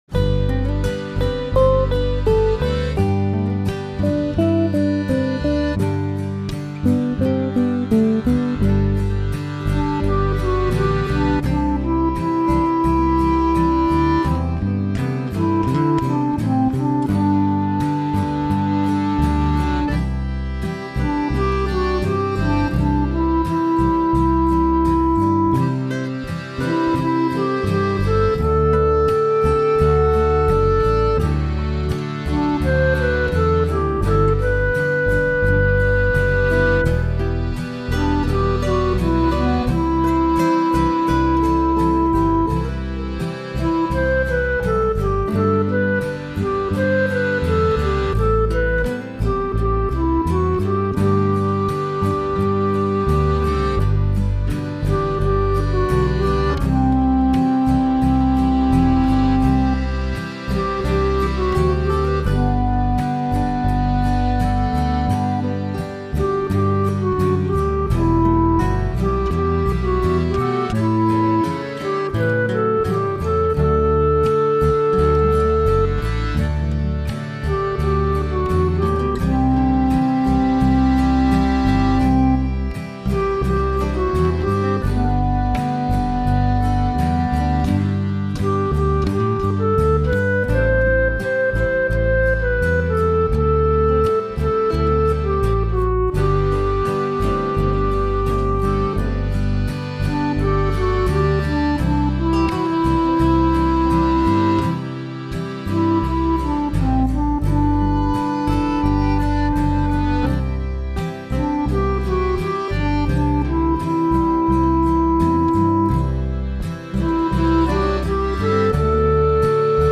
The tune sounds like Felice Navidad in parts.